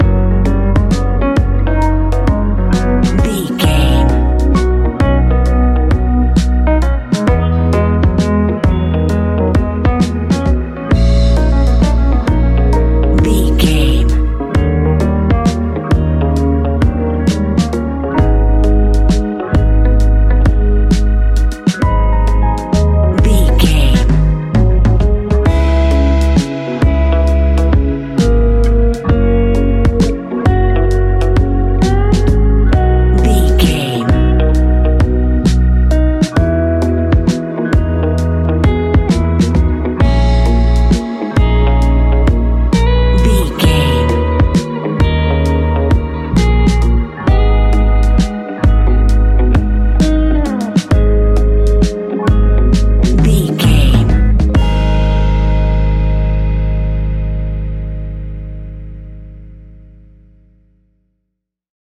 Ionian/Major
G♭
laid back
new age
chilled electronica
ambient
atmospheric